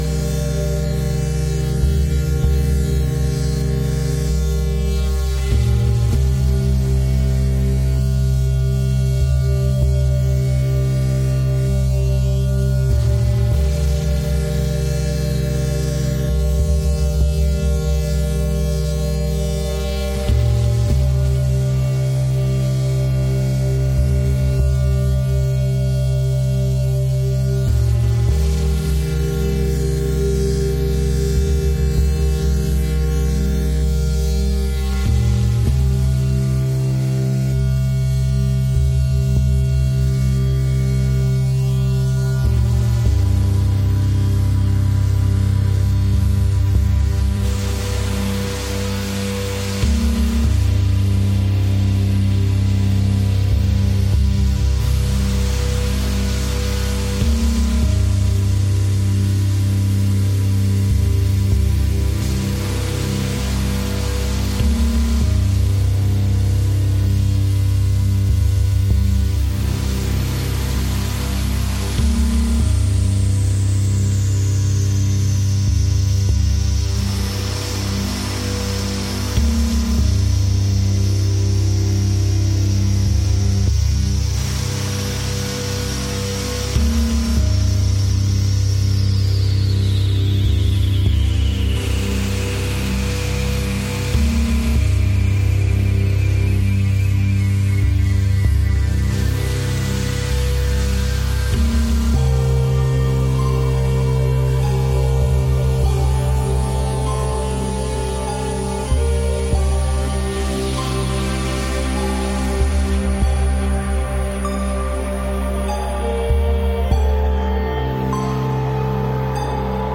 mystic drumming, psychedelia & syncretic sonic traditions
Electronix Ambient